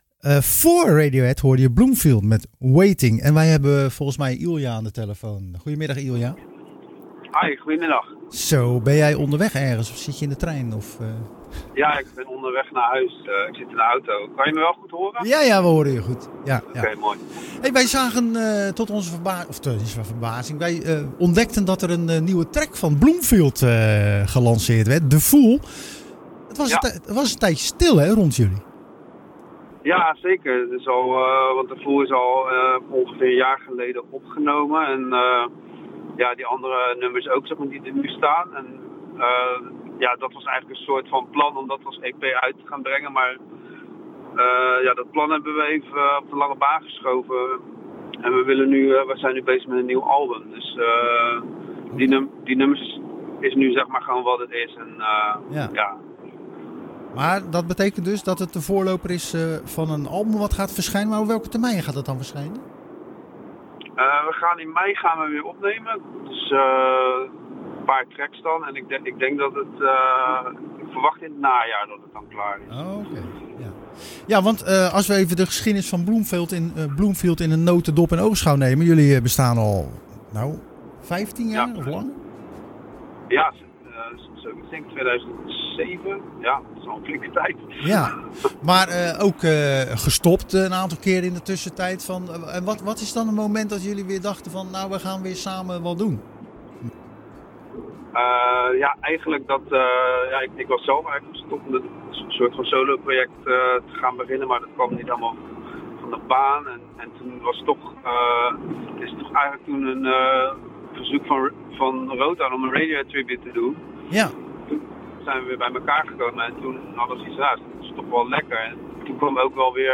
De Rotterdamse band acteert al zo'n 15 jaar in het circuit.